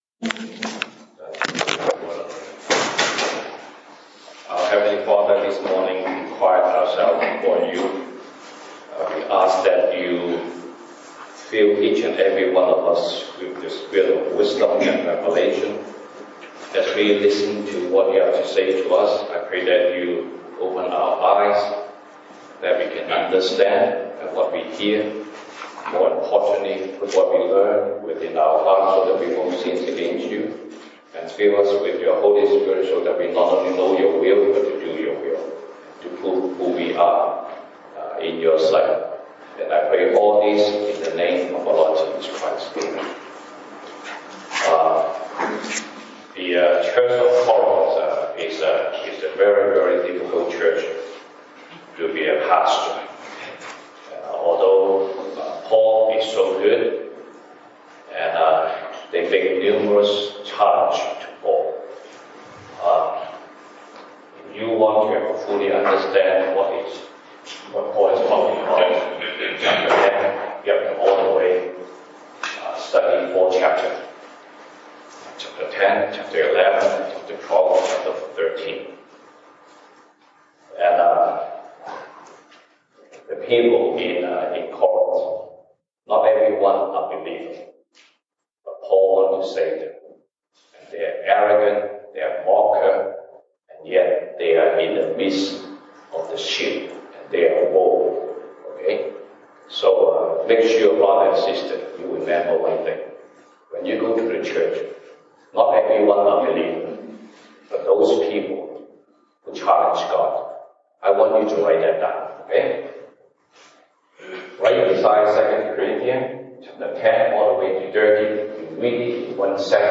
西堂證道(粵語/國語) Sunday Service Chinese: 用言行證明你是誰